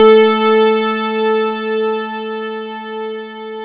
KORG A4  1.wav